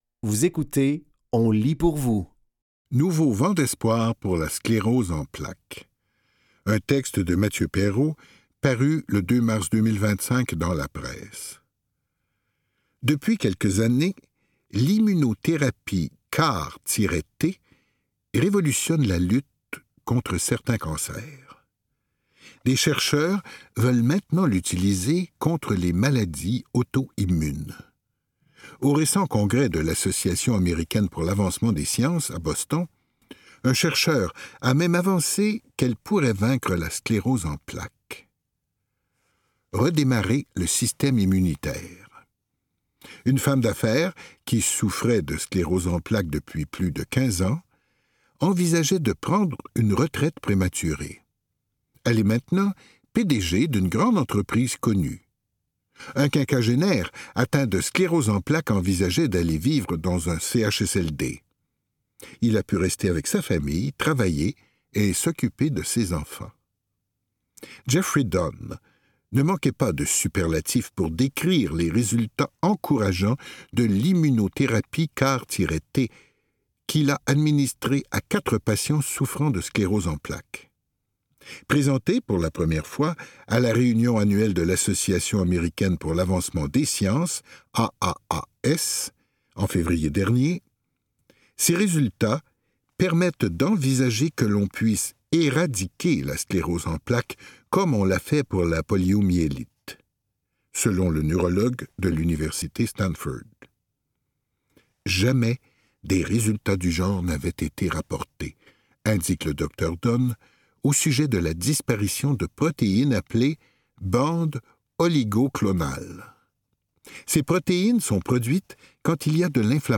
Dans cet épisode de On lit pour vous, nous vous offrons une sélection de textes tirés des médias suivants: La Presse, Le Devoir et Fugues.